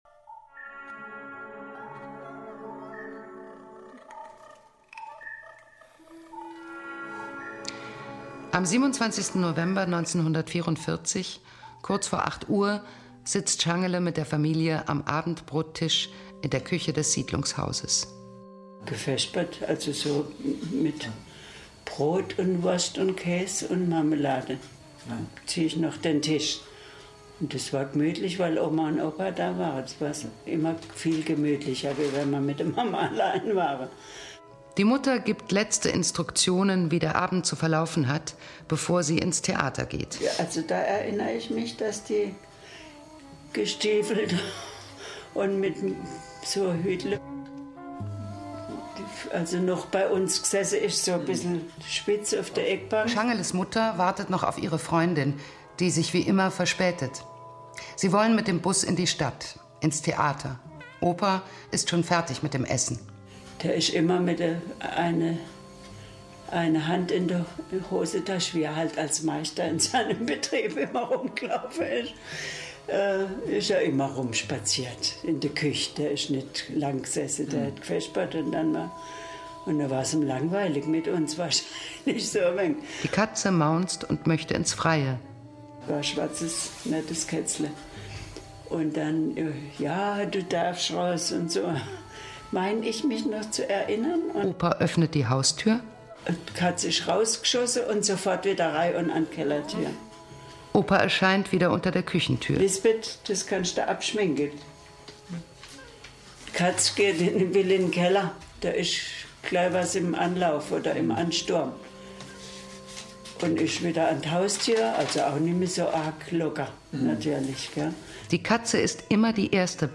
Unter besonderer Berücksichtigung eines fächerverbindenden Ansatzes im Schnittfeld von Geschichts- und Deutschunterricht untersuchen die Schülerinnen und Schüler ein Hörspiel.